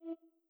rotate.wav